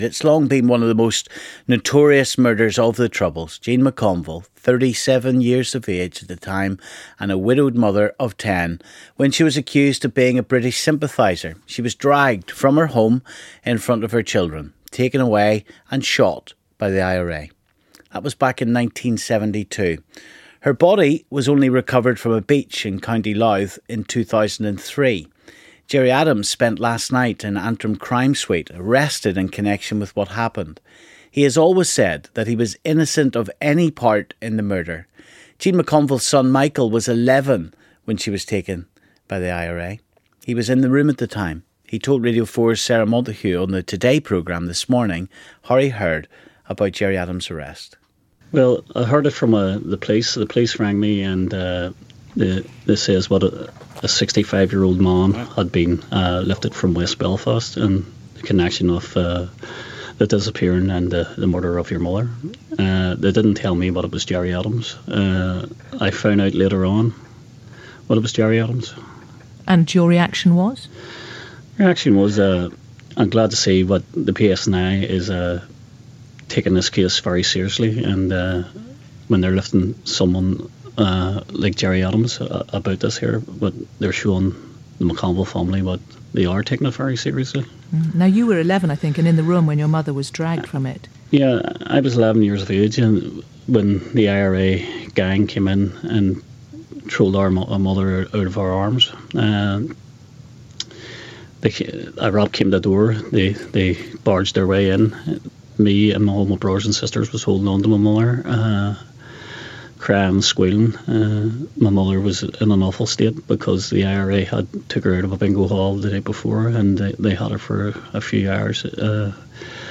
on Radio 4 Today Programme this morning